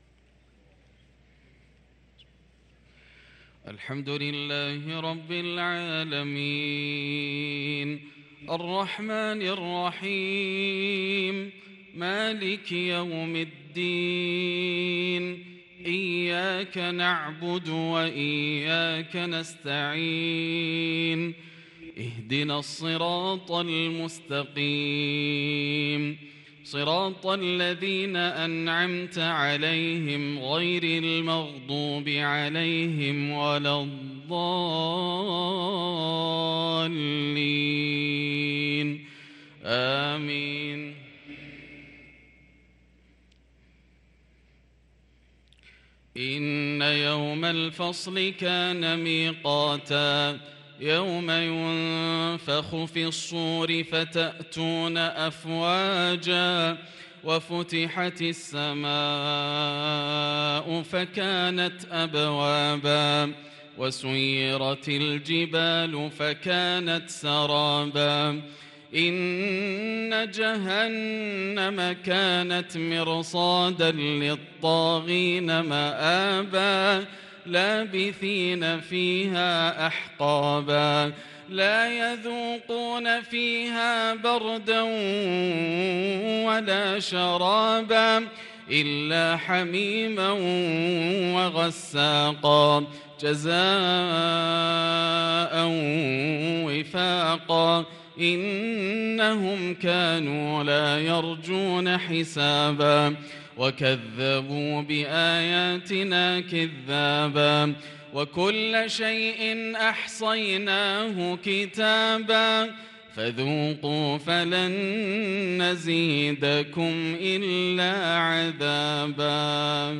صلاة العشاء للقارئ ياسر الدوسري 2 صفر 1444 هـ
تِلَاوَات الْحَرَمَيْن .